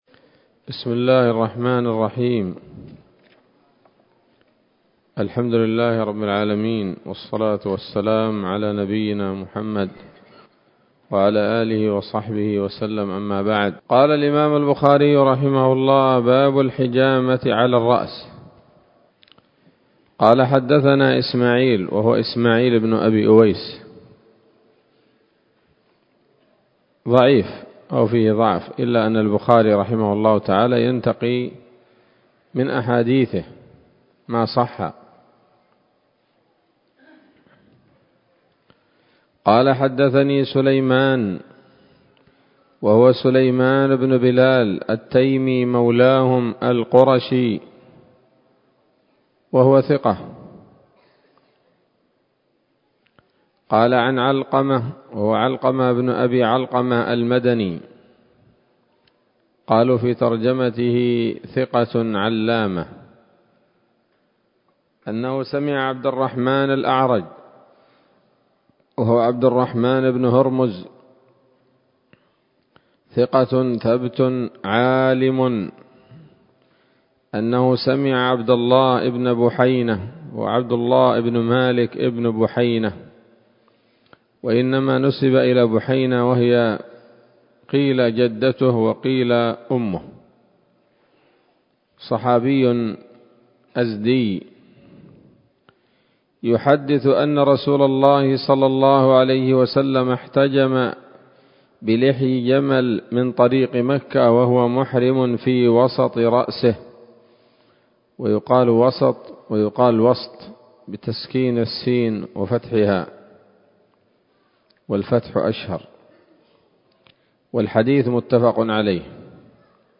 الدرس الحادي عشر من كتاب الطب من صحيح الإمام البخاري